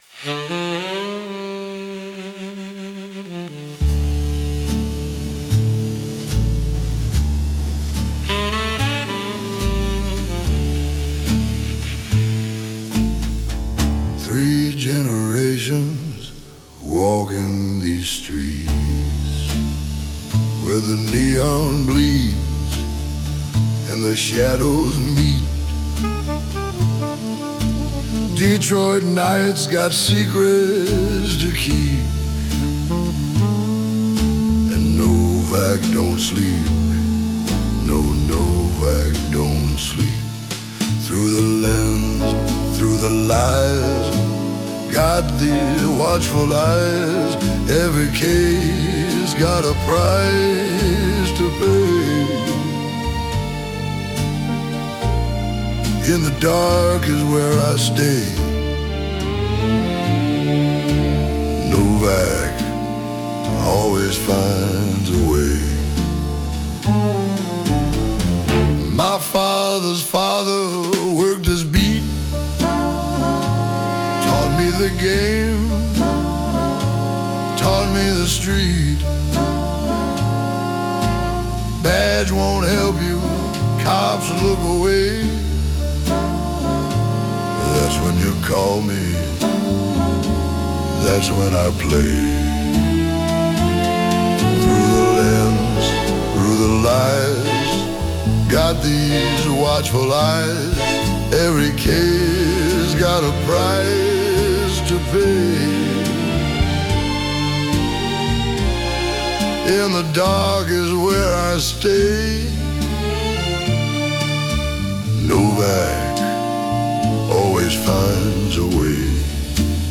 Baritone Vocals
Piano
Saxophone
Guitar
Drums
Upright Bass
Backing Vocals
Main Theme